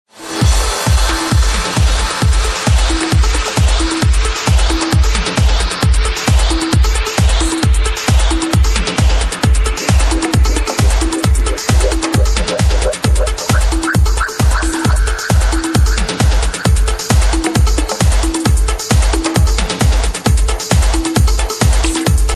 prog trance-ish
There's a distinct sound though, kind of a latin beat.